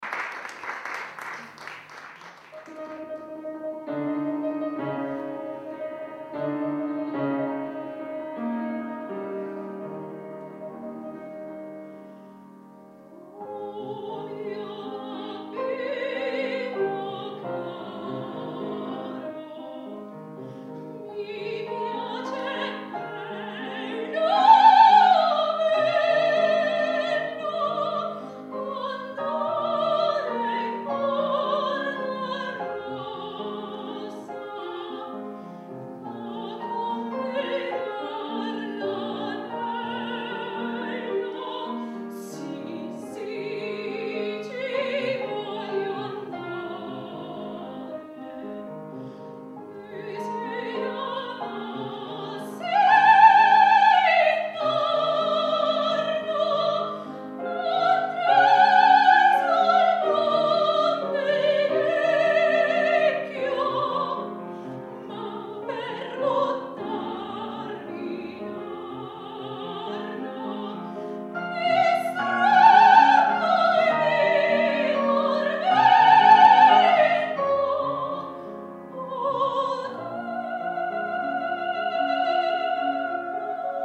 17 ottobre 2009 - II OTTOBRE MUSICALE A PALAZZO VALPERGA - Concerto - Arie Italiane